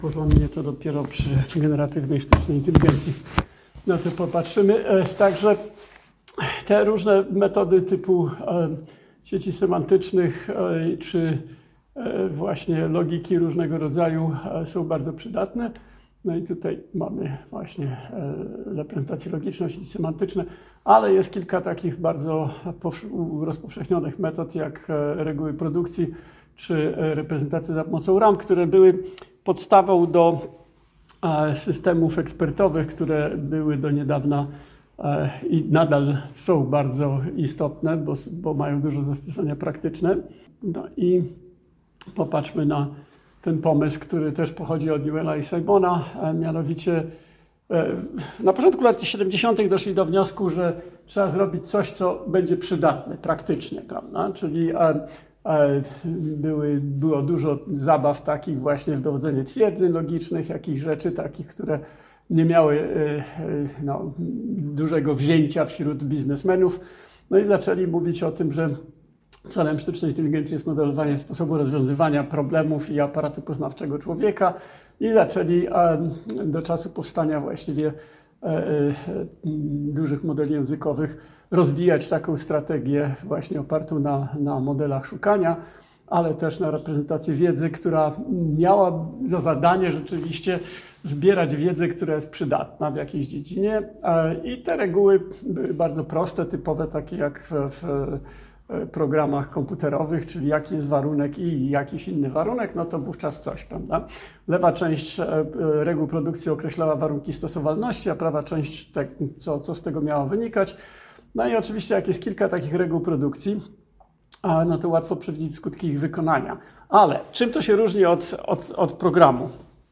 Sieci rozchodzących się aktywacji, sieci semantyczne i grafy wiedzy. 6.2 Systemy produkcyjne, ramy, skrypty, mapy argumentów 6.3 Agenci GOFAI, nagranie wykładu 6.2 i 6.3., 10.04.2025.